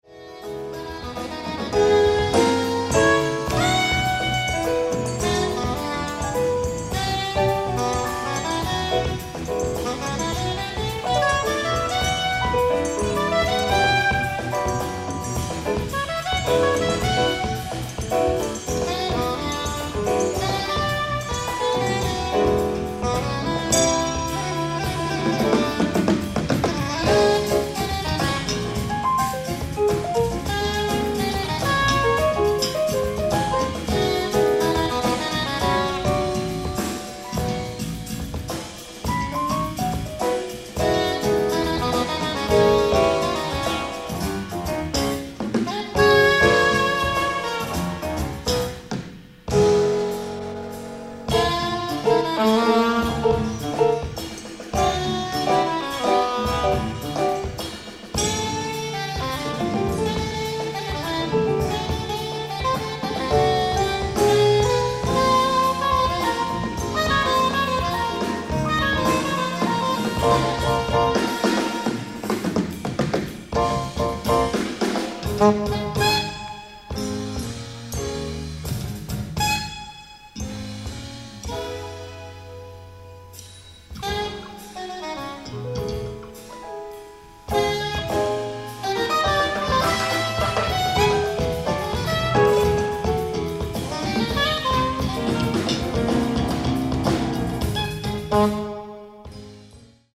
ライブ・アット・IMAC・シアター、ハンティントン、ニューヨーク 09/05/1987
サウンドボード級の極上オーディエンス音源盤！！
※試聴用に実際より音質を落としています。